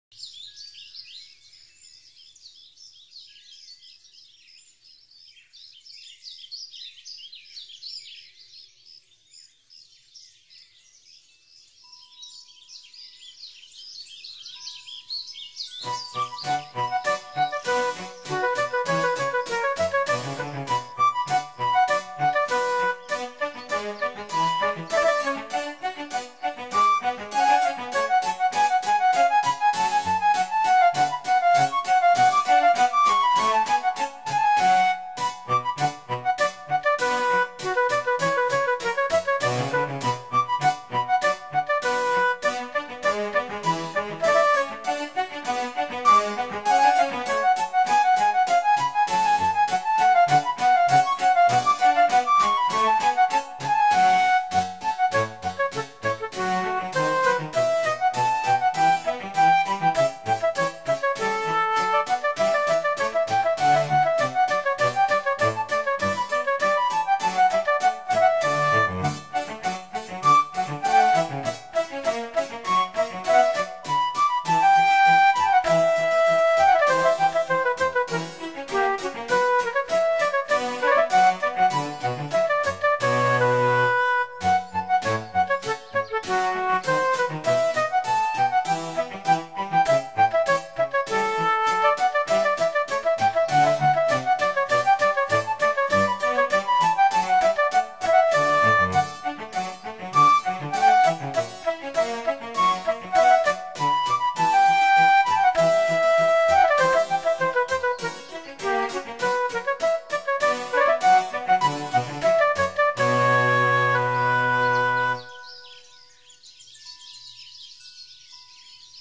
那轻快的节奏，俏皮的旋律
动感而优美！